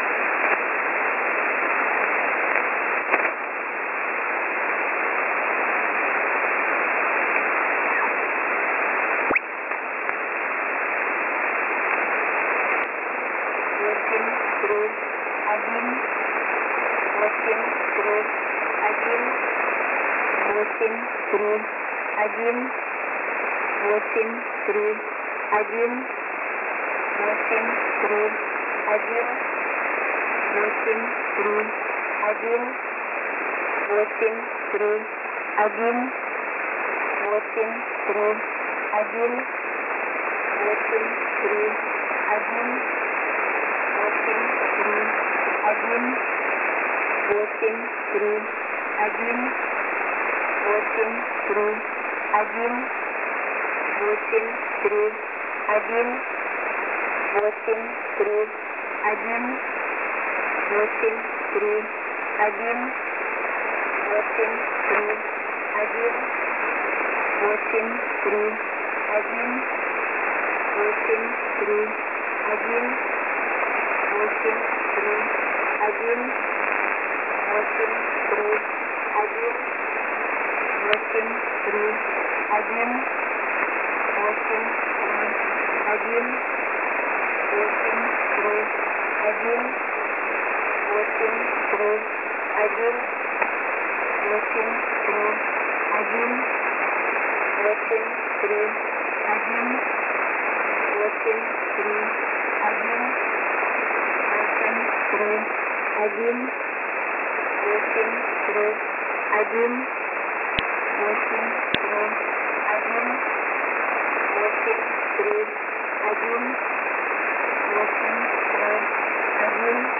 Mode: USB + Carrier